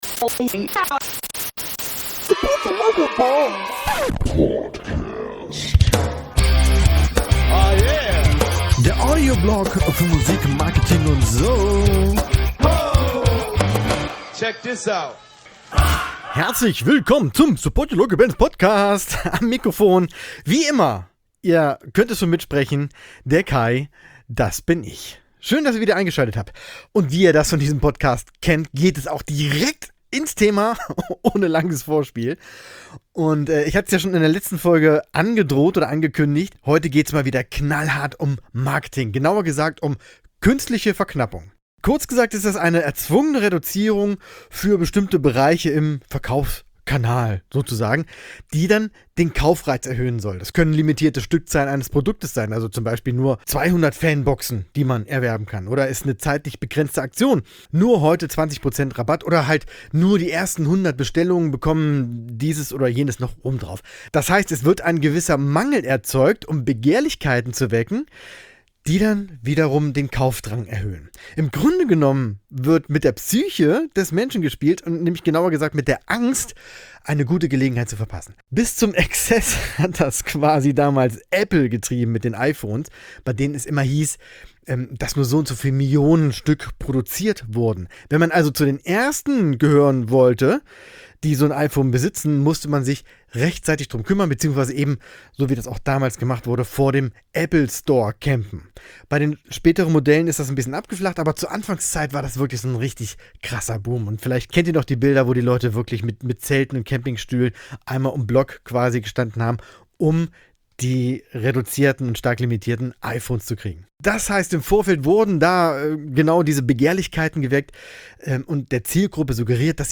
In dieser Folge gibt es wieder ein (wahrscheinlich weltweit) neues Format: der Reaction-Podcast! Ihr kennt die Logik vielleicht schon von Youtube, bei der ein vorhandenes Video oder ein Song oder irgendwas genommen und die eigene Reaktion darauf gefilmt wird.